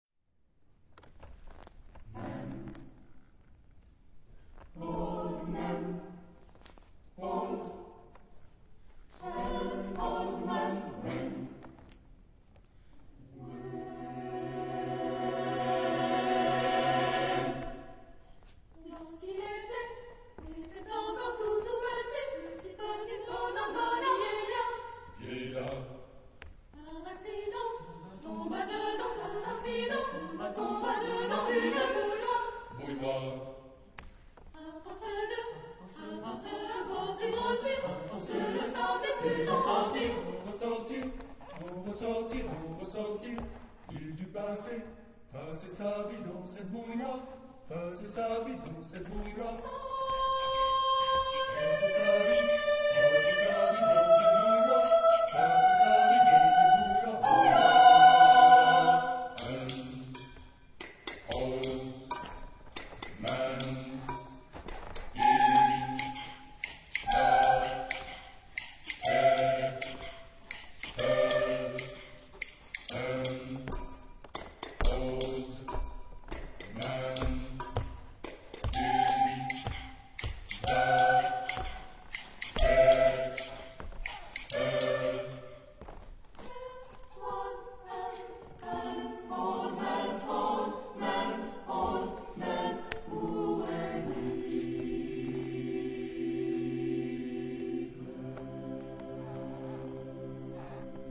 Genre-Stil-Form: weltlich ; zeitgenössisch ; humoristisch
Charakter des Stückes: humorvoll
Chorgattung: SATB  (4 gemischter Chor Stimmen )